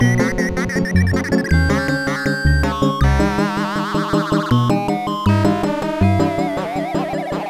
EXAMPLE 2: Pitch Modulation used in a solo (along with some wide vibratos):
c700_pmod_solo.mp3